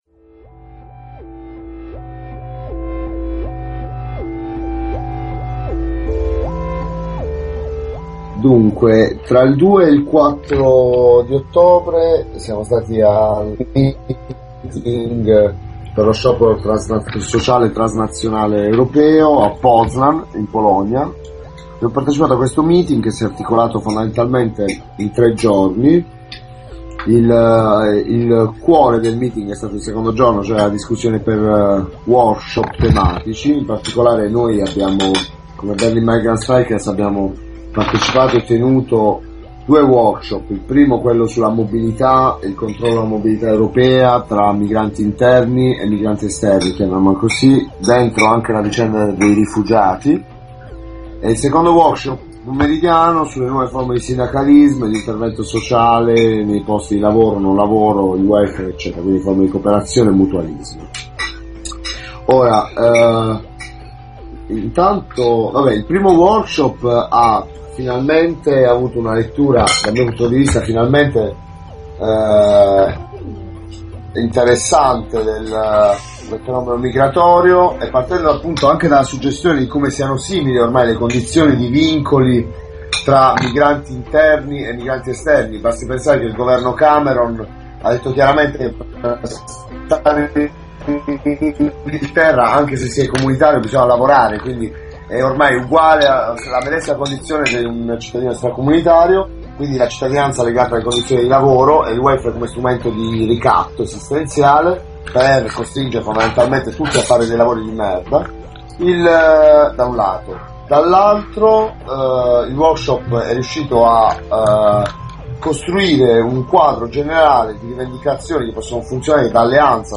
Report audio di BERLIN MIGRANT STRIKERS dello STRIKE MEETING -per uno sciopero sociale- che si è tenuto in questi giorni a Poznan (Polonia). Quali temi? Chi ha partecipato? Cosa si organizzerà in futuro?